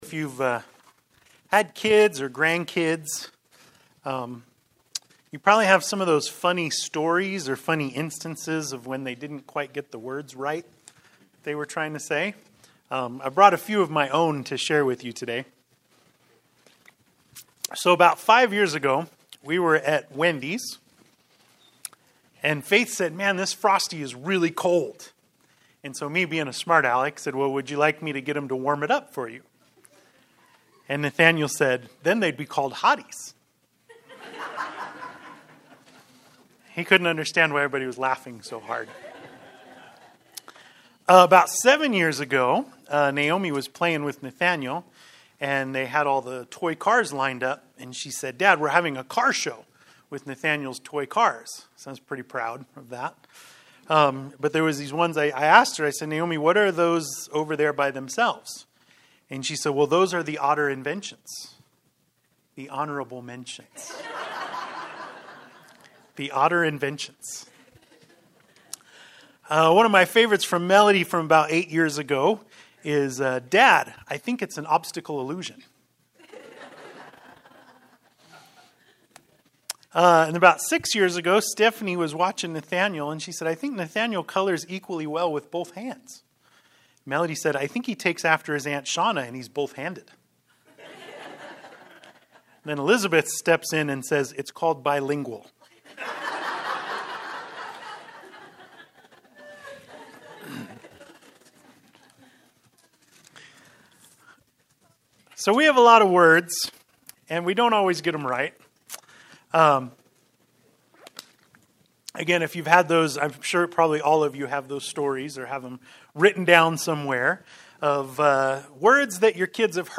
Sermon-4-30-23.mp3